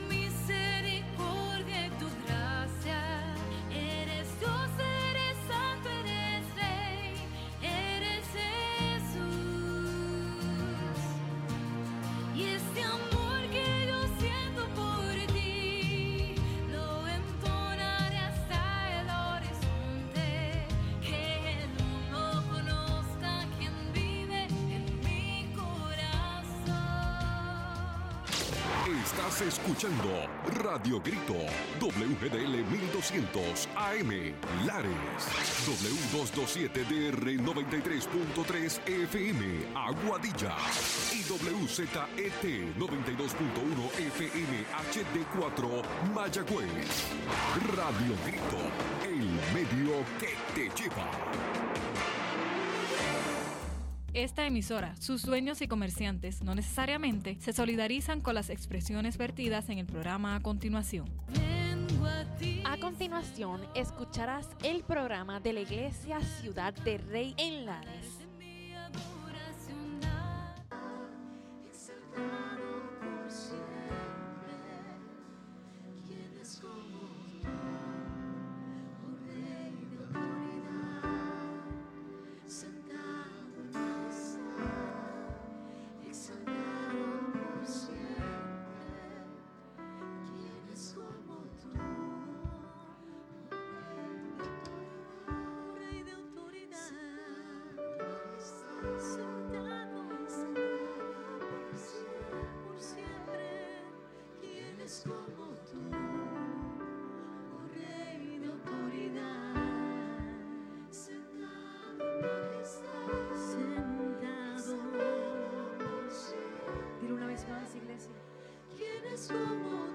Los hermanos de Ciudad del Rey nos traen un programa especial de su servicio en la iglesia.